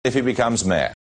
Here he is again, saying if he becomes mayor with mɛː for mayor: